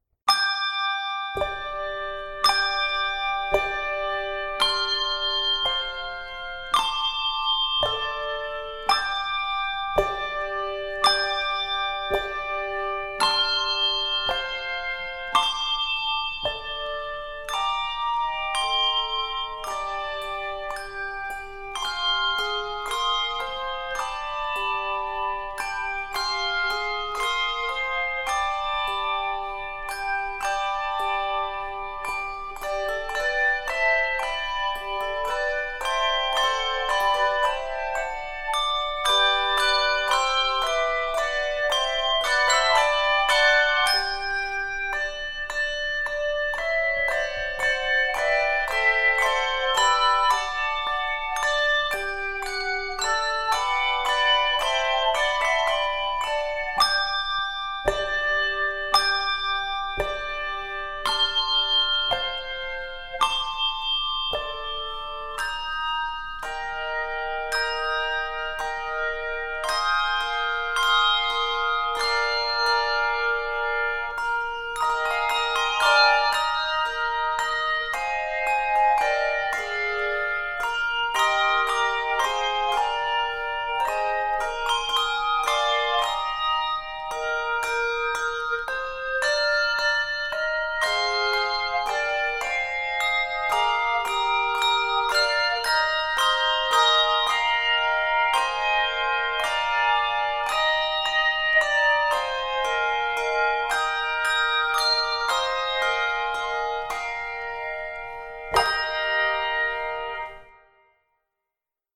worshipful two-octave arrangement
Keys of G Major and Eb Major.